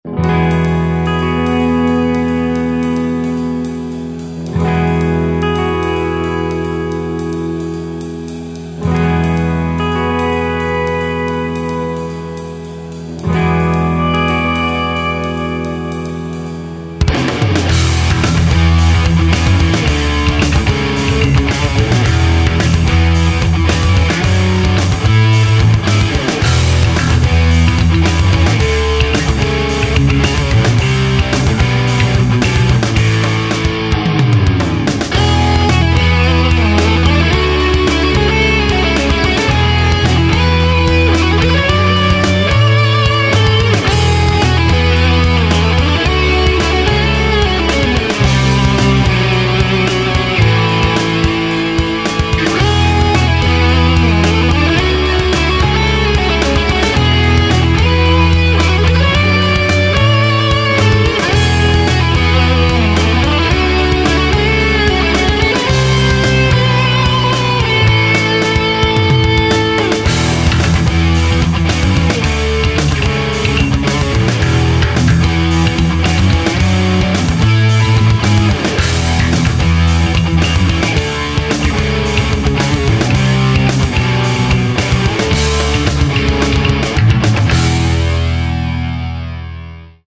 pekne, dobry zvuk to ma.  v com si to robil? bicie a tak ..
Je to tranzistor Mashall valvestate 100W komo.
Inak gitara je ziva aj basa a bicie fruity loops.
Fajn ukazky, prijemne sa to pocuva, pekne melodicke motivy.